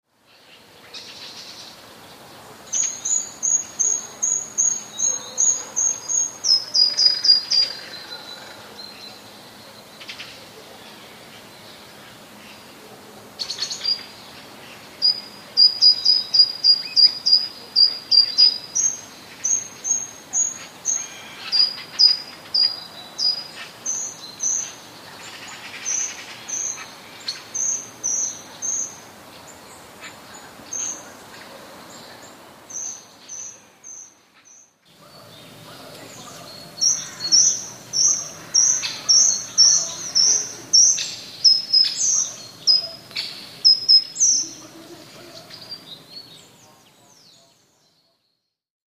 Przykłady śpiewu pełzaczy pochodzą z moich własnych nagrań.
Pełzacz ogrodowy - Certhia brachydactyla
wysokie głosy pełzaczy.